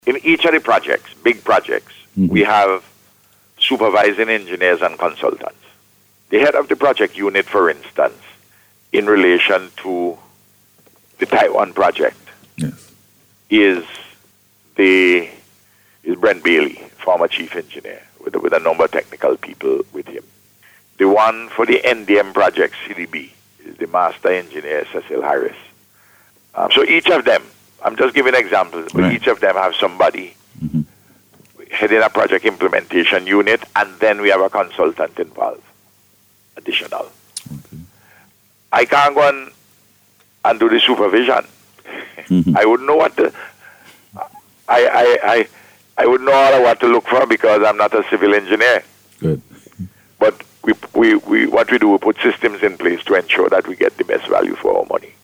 Dr. Gonsalves gave the assurance, as he provided further information on the Road Programme, during NBC’s Face to Face programme this morning.